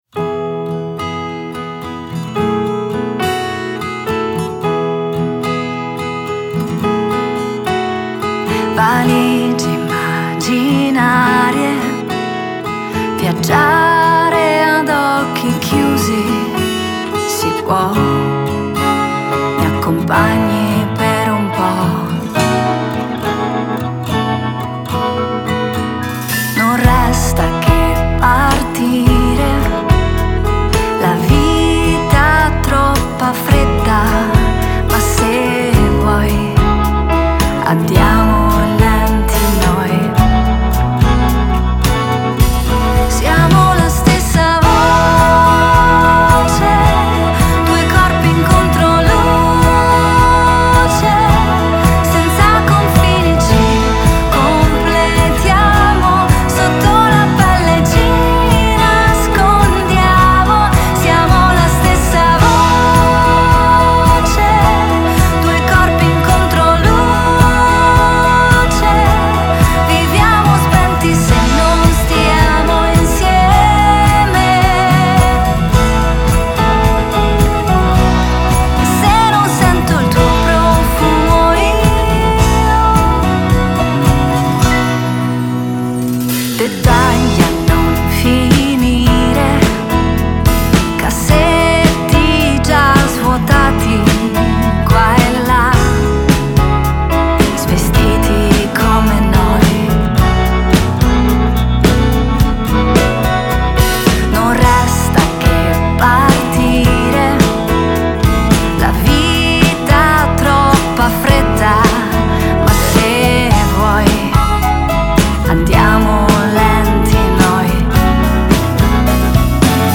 Genere: Pop.